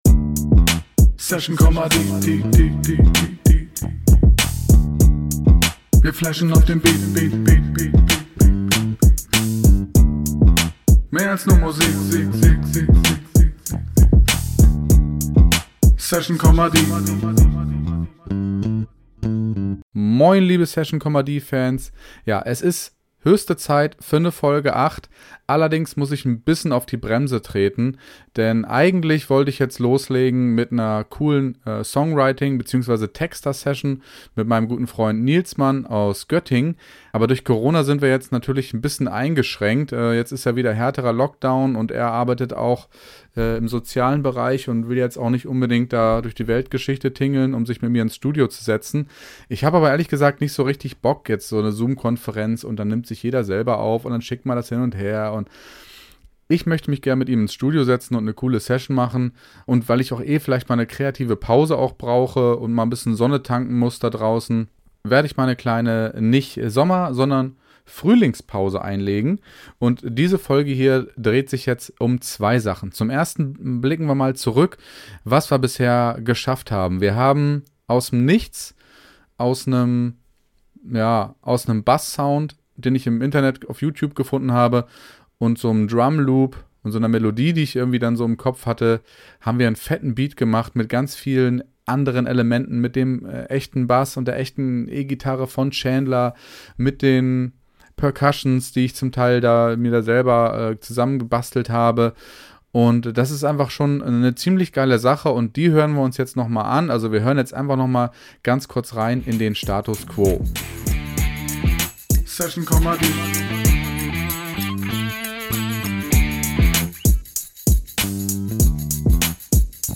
Das heißt, ihr kriegt keine langweilige "Theoriestunde", sondern in knackigen 10-11 Minuten ein bisschen Musik auf die Ohren und die dazugehörigen Infos, wie man dazu kommt, einen Rap- bzw. Songtext zu schreiben.